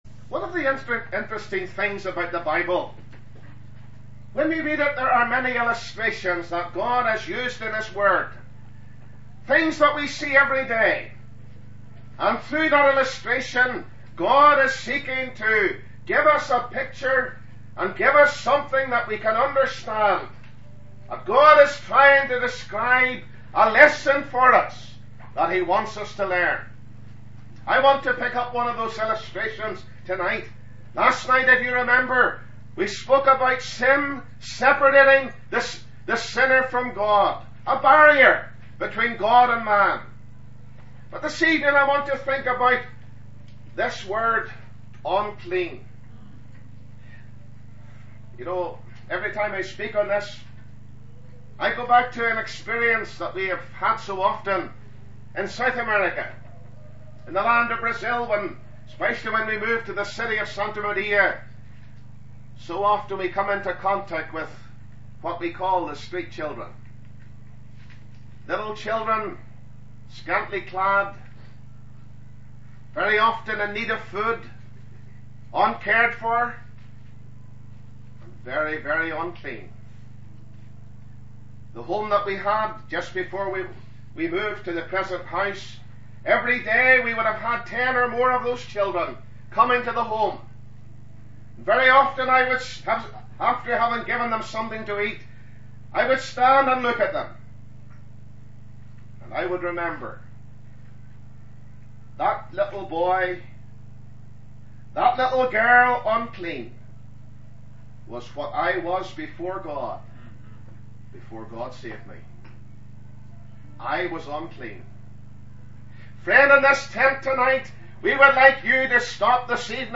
2007 Gospel Tent